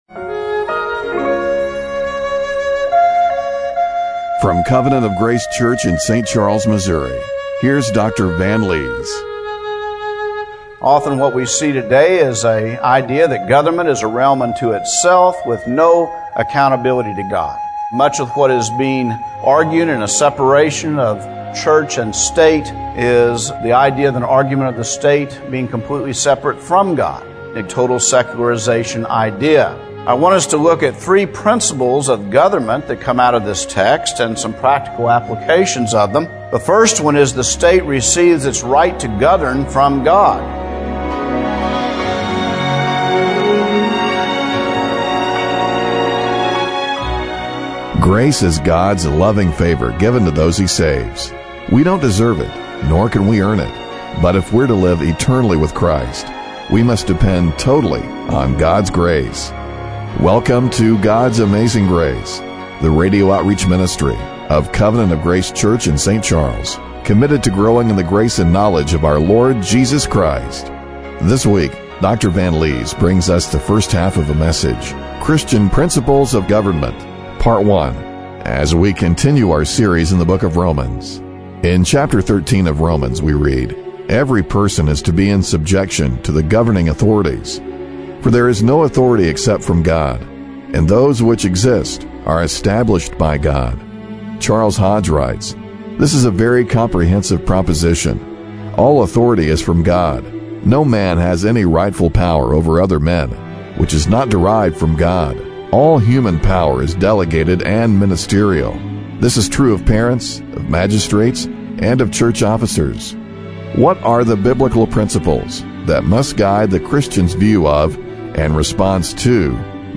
Romans 13:1-7 Service Type: Radio Broadcast What are the Biblical principles that must guide the Christian's view of and response to governing authorities?